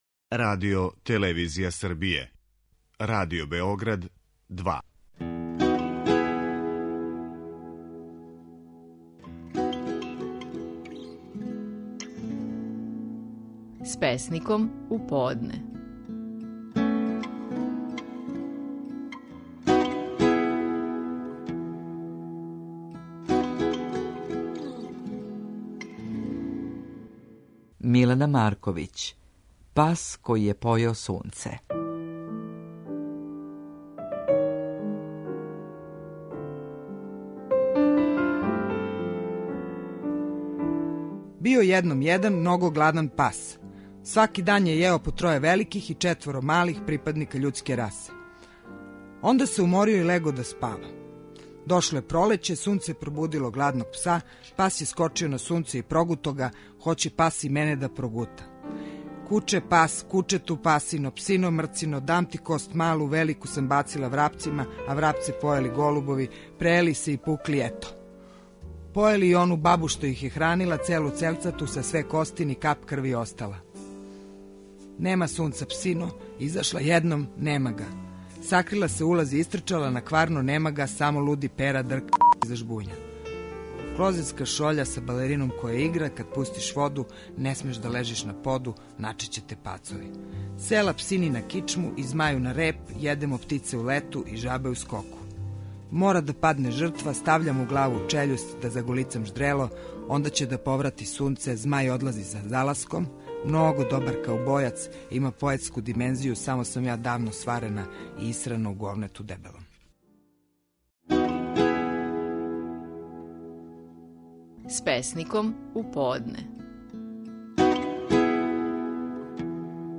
Стихови наших најпознатијих песника, у интерпретацији аутора.
Милена Марковић, казује песму „Пас који је појео сунце".